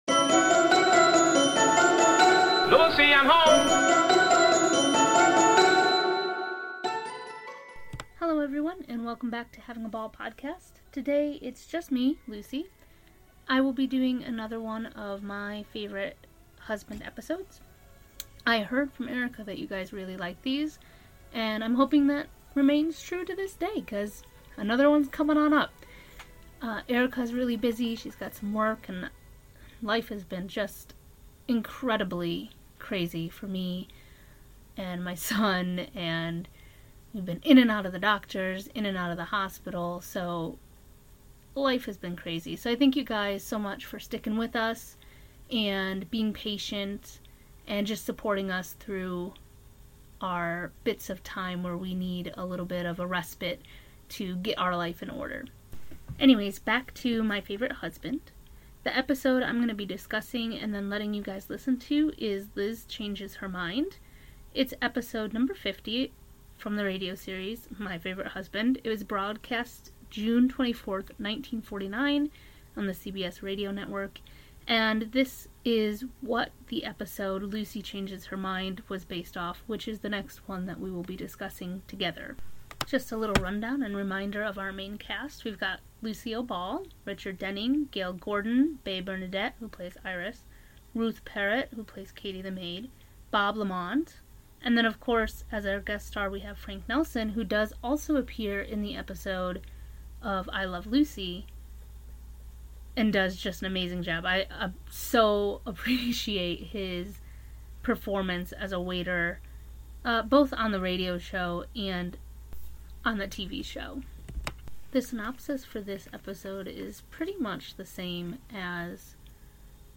we get to travel back in time and listen to the original broadcast from June 24, 1949.